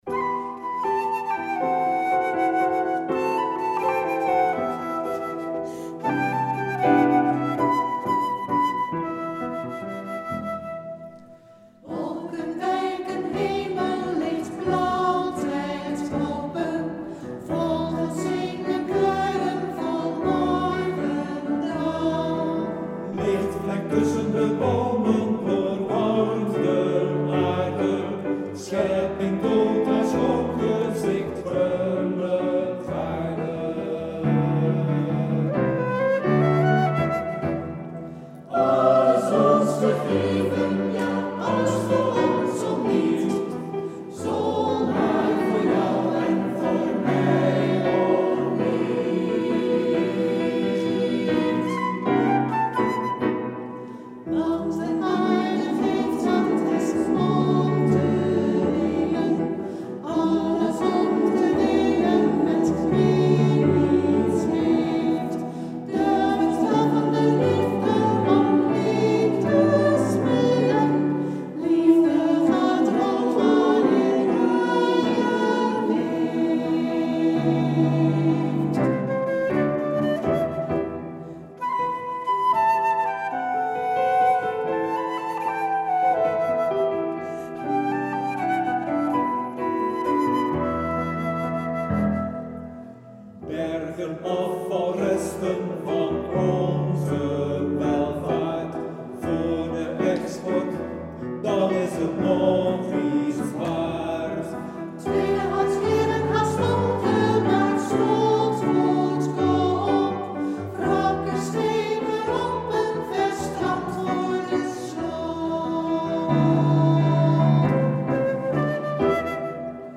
fluit
piano